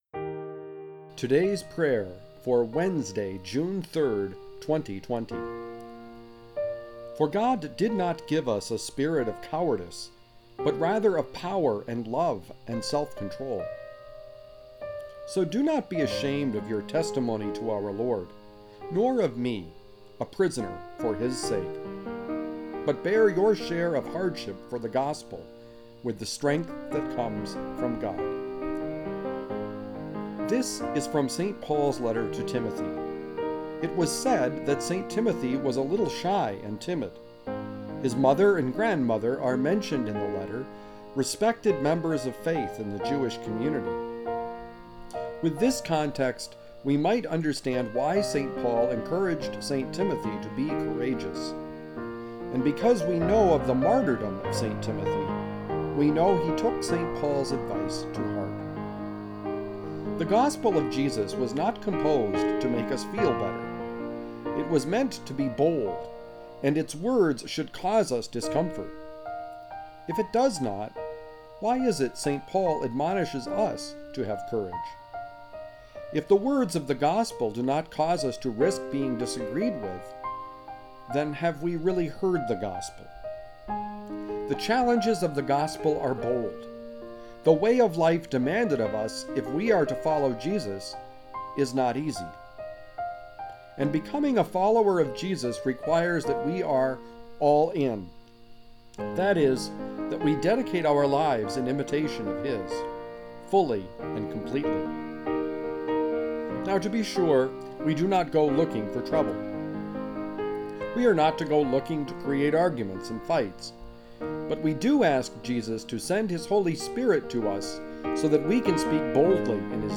Music: Cheezy Piano Medley by Alexander Nakarada